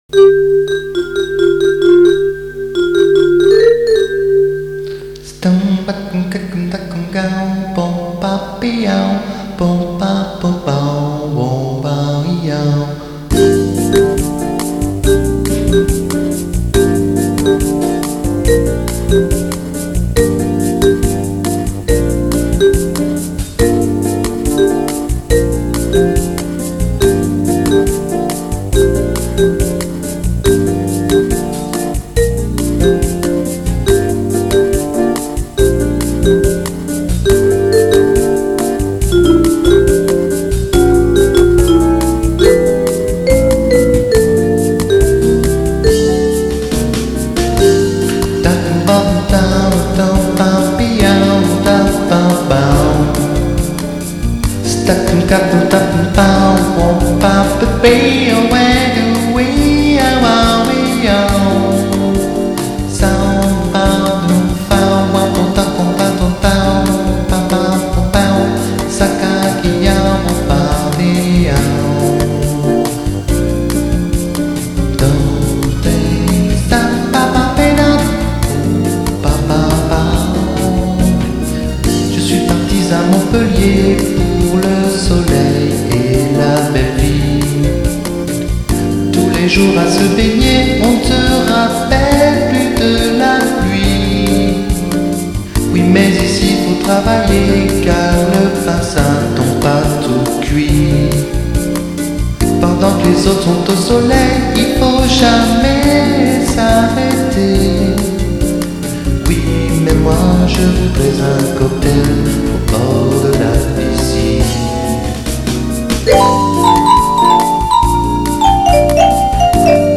Easy listening à écouter avec un cocktail. 2004
aller à Jazz !